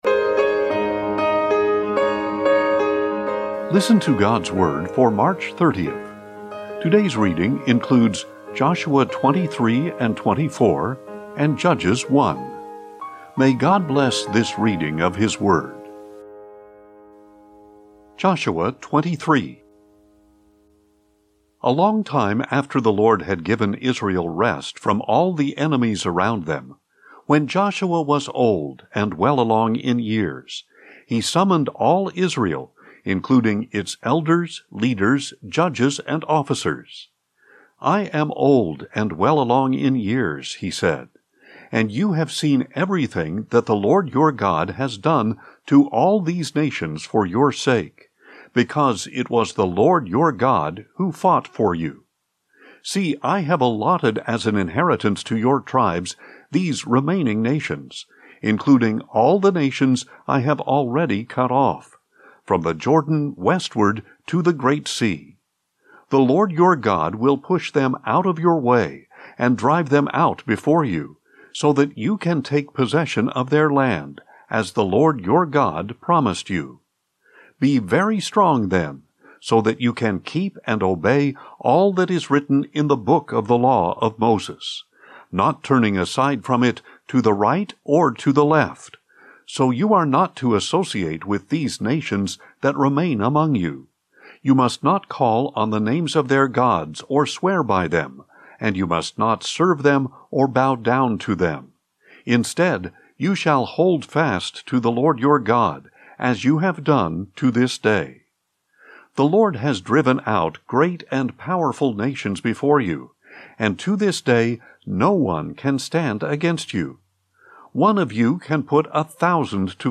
Daily Bible Reading for March 30